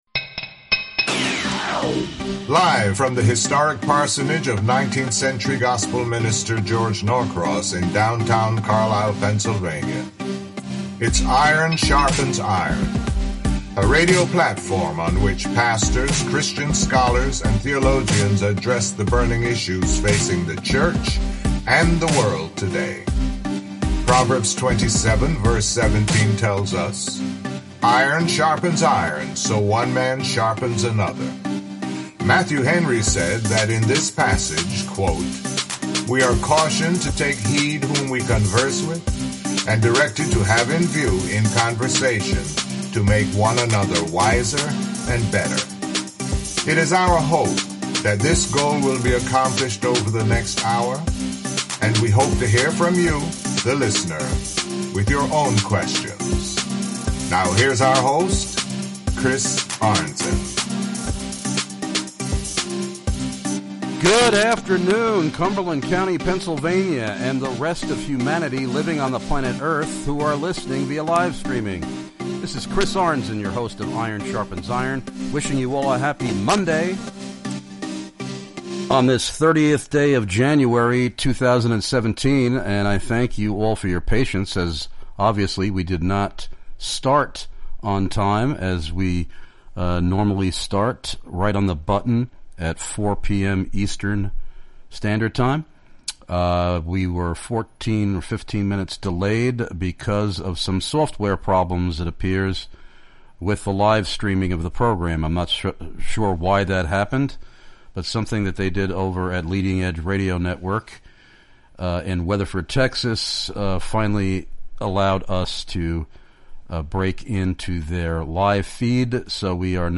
As a result, you will notice some background conference noise–hopefully it will help to convey the excitement at this year’s convention!